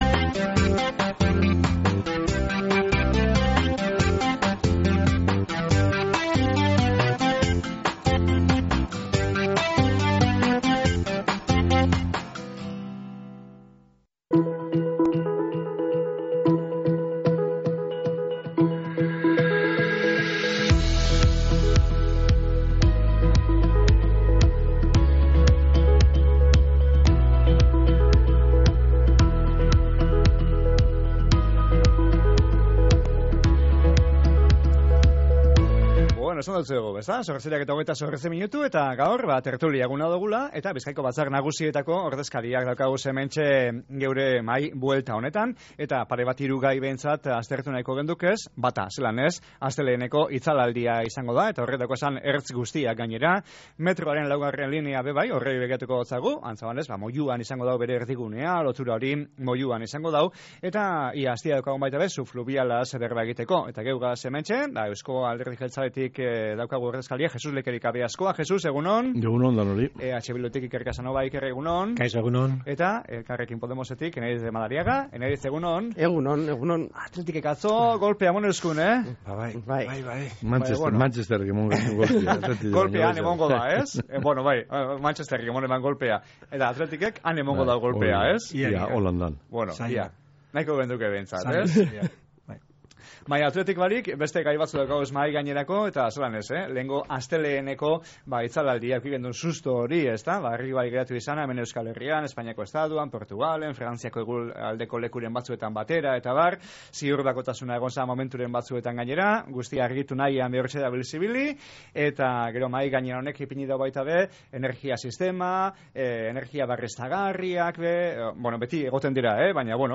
Gaion gaineko hausnarketak egin deuskuez EAJ, EH Bildu eta Elkarrekin Podemoseko batzarkideek